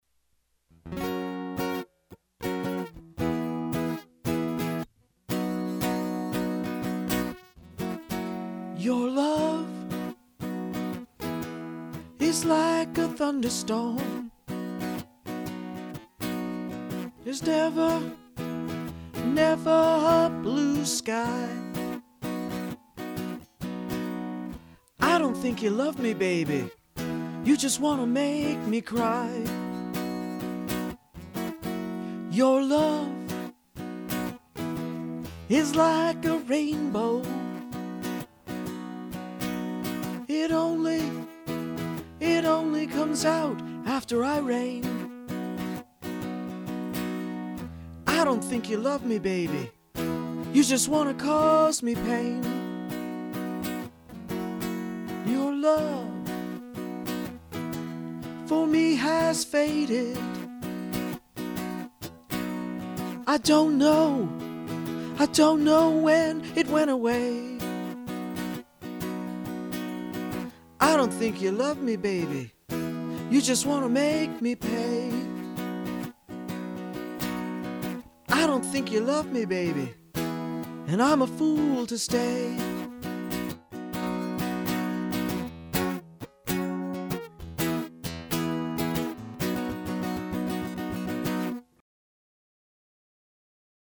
Politically Incorrect Modern Blues